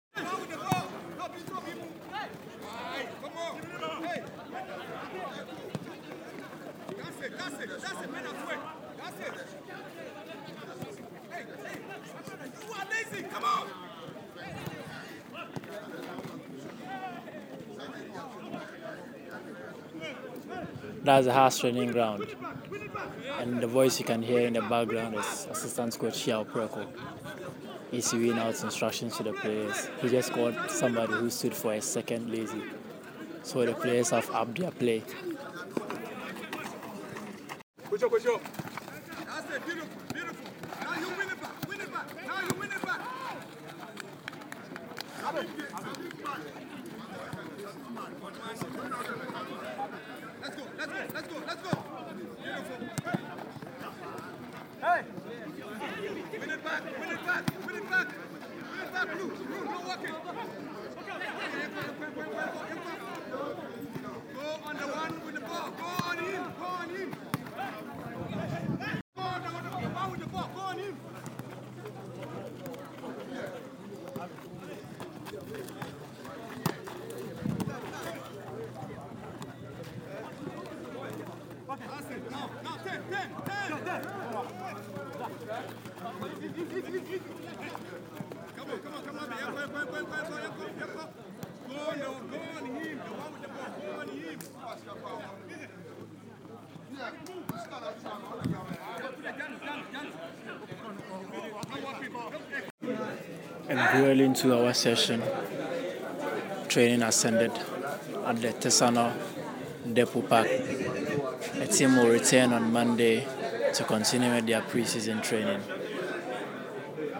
SOUNDS FROM HEARTS TRAINING
In the background, assistant coach, Yaw Preko, is charging the boys to up their play.